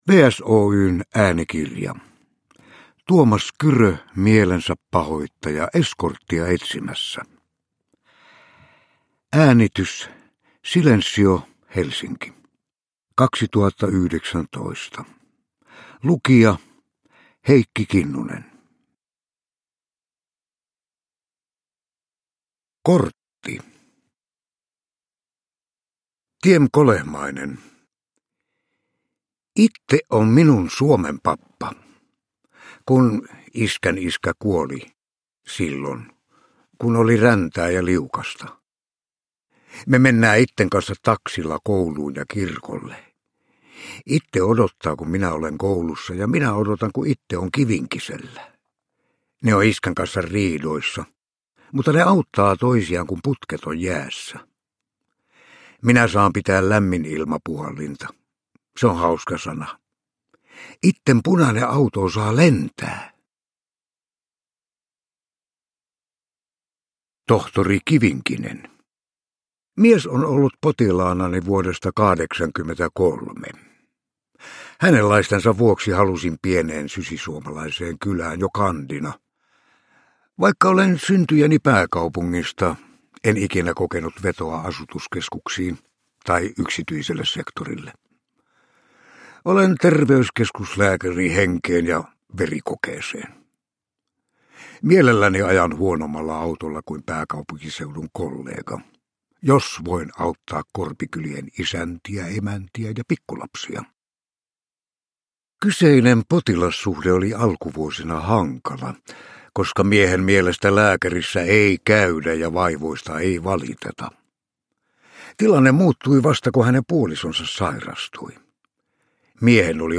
Mielensäpahoittaja Eskorttia etsimässä – Ljudbok
Uppläsare: Heikki Kinnunen